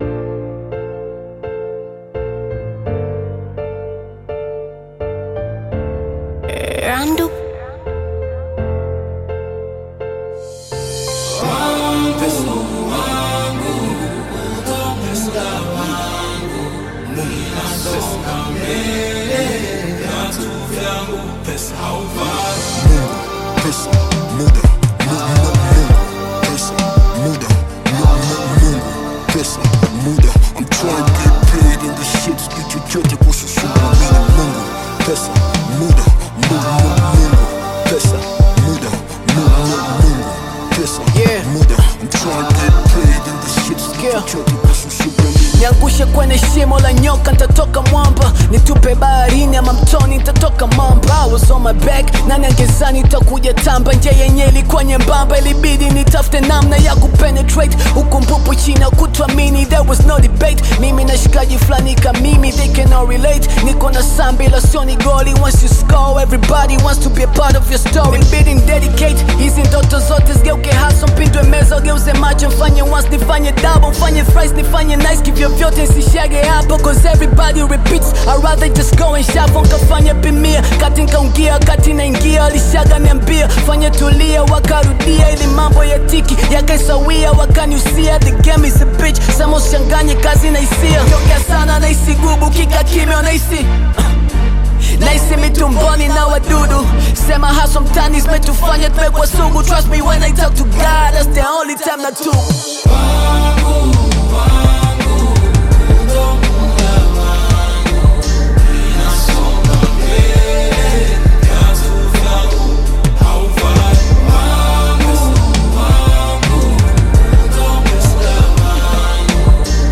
Bongo Flava music track
Tanzanian Bongo Flava Hip-Hop